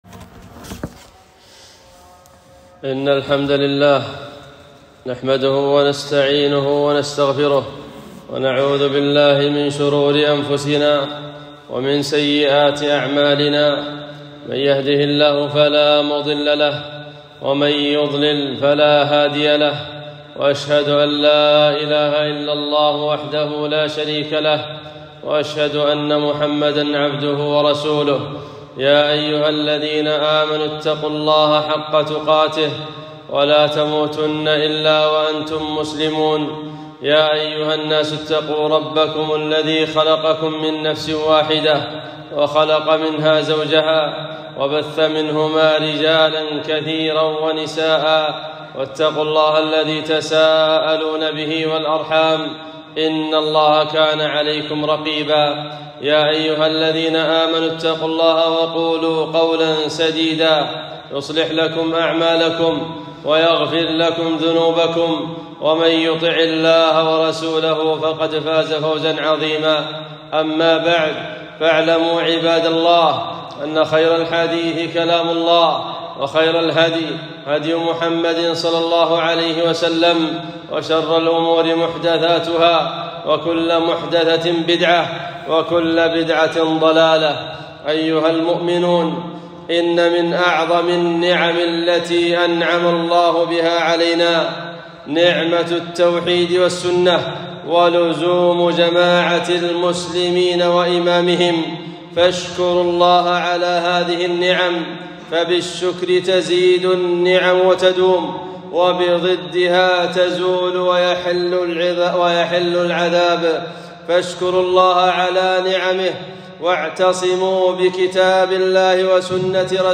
خطبة - جماعة التبليغ ( الأحباب )